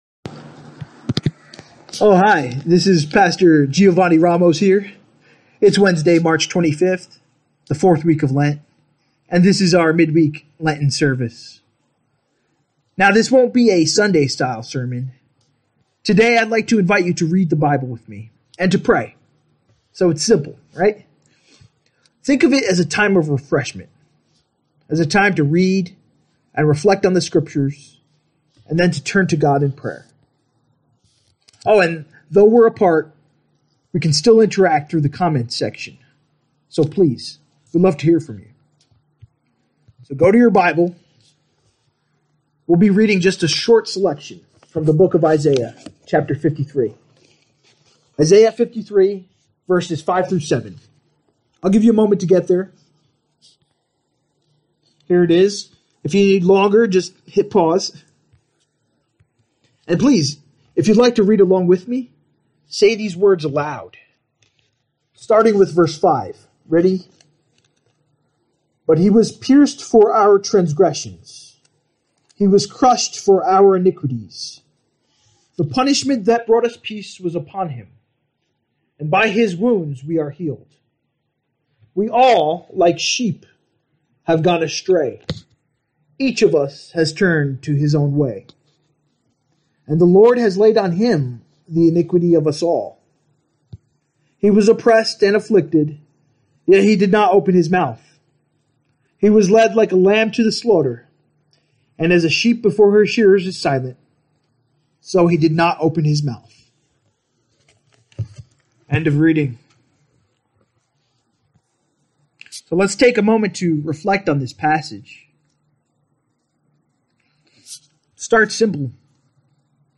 Lenten-Service-32520.mp3